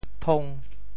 酮 部首拼音 部首 酉 总笔划 13 部外笔划 6 普通话 tóng 潮州发音 潮州 tong5 文 中文解释 酮〈名〉 一类有机化合物(如丙酮) [ketone],其特征是含有与两个碳原子相连接的羰基,这两个碳原子通常包含在两个烃基中(如在通式RCOR中)或者包含在一个二价基中,酮与醛相似,但不如醛活泼 酮tóng有机化合物的一类，通式R-CO-R'。
thong5.mp3